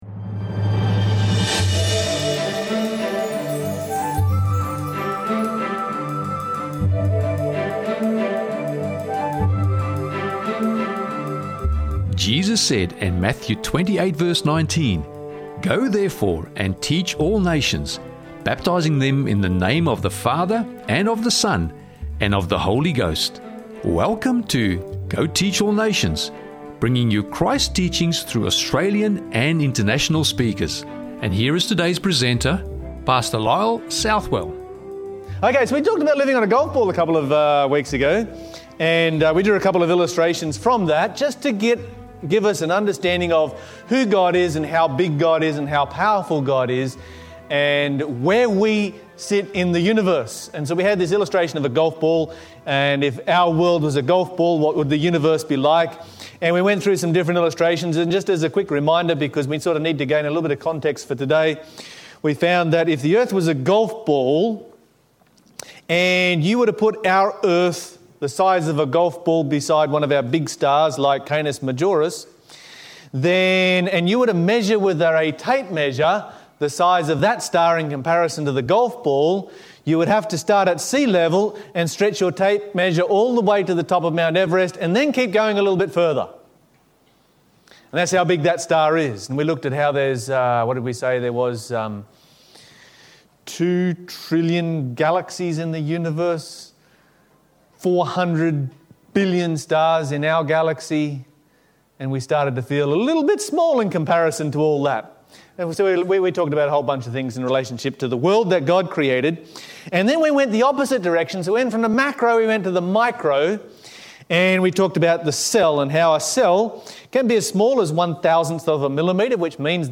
Evidence, Faith & the God Who Made Everything - Sermon Audio 2607